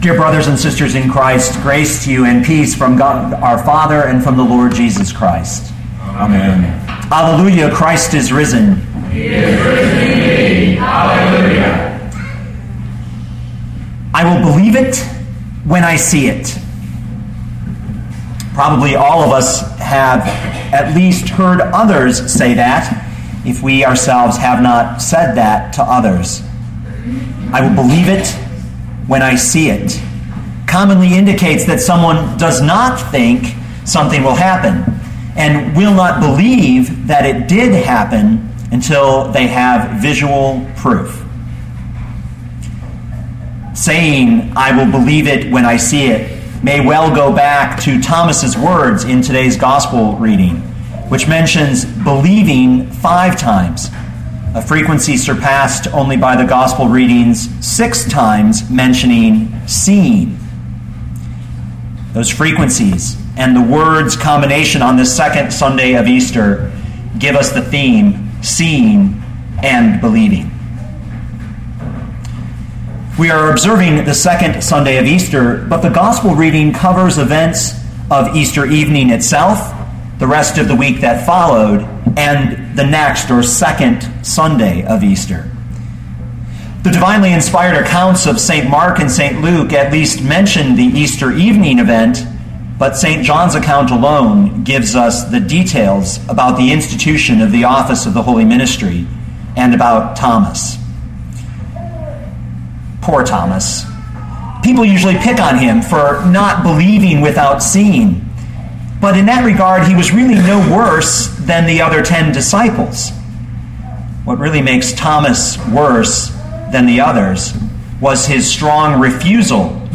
2013 John 20:19-31 Listen to the sermon with the player below, or, download the audio.